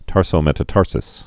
(tärsō-mĕtə-tärsəs)